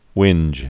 (wĭnj, hwĭnj)